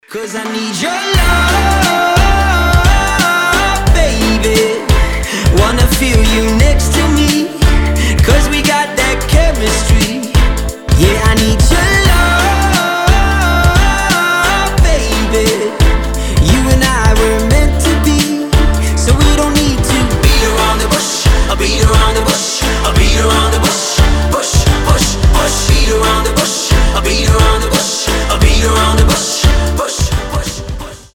мужской вокал
мелодичные
регги-поп
reggae-pop